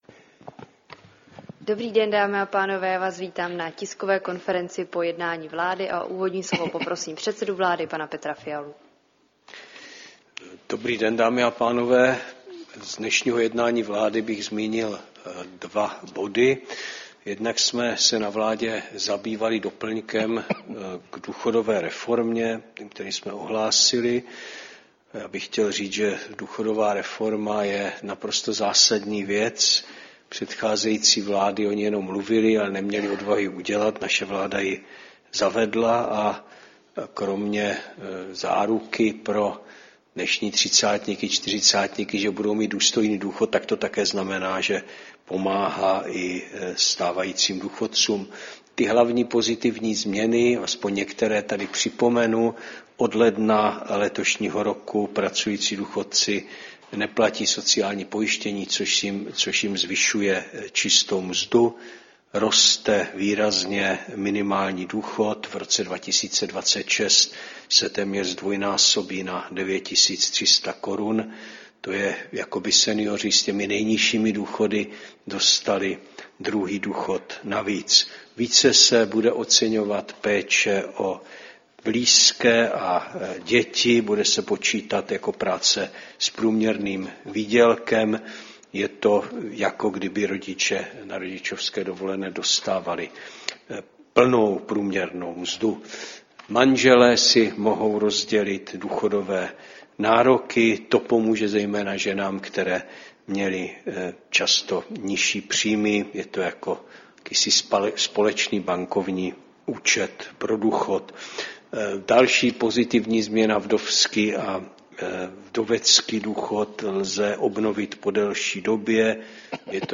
Tisková konference po jednání vlády, 29. ledna 2025